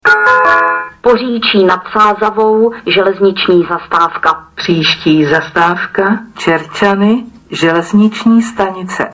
Vzhledem k tomu, že náhradní dopravu zajišťovaly autobusy Dopraního podniku hlavního města Prahy, ve všech spojích (včetně toho do Čerčan) byly vyhlašovány zastávky standardním způsobem.
- Hlášení "Poříčí nad Sázavou, železniční zastávka. Příští zastávka Čerčany, železniční stanice" si